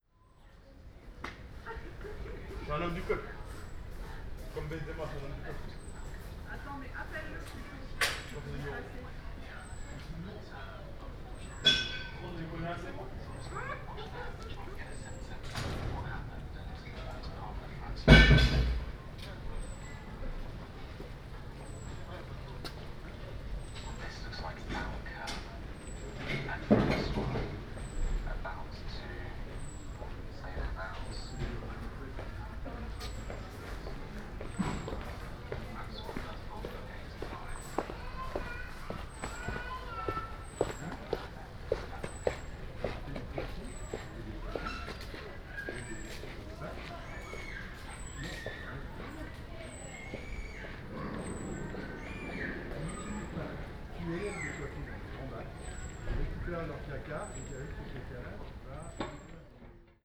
Une voie privée calme, entourée de maisons dont on n’ose pas imaginer les prix ou les loyers.
Appuyé contre un mur, les micros dans les oreilles, faire semblant d’être absorbé dans son smartphone, pendant que les passant passent. Son binaural, au casque c’est mieux.
Paris, 26/10/2022